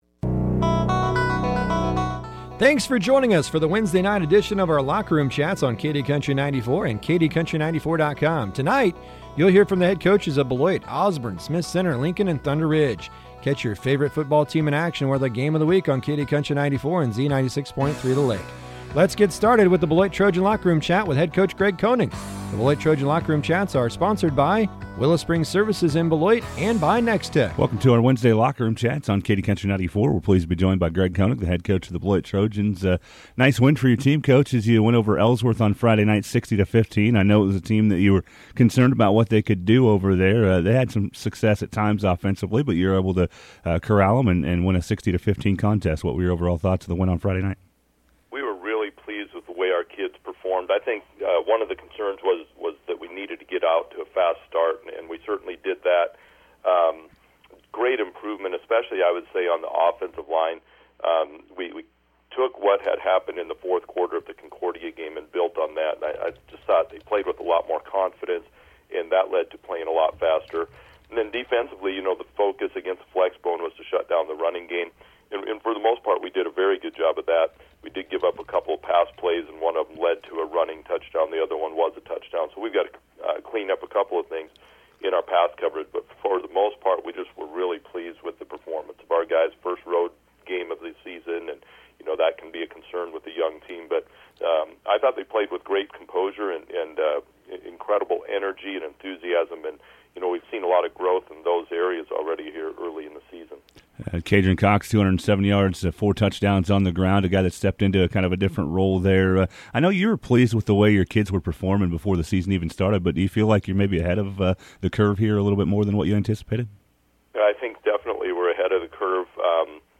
chats with head coaches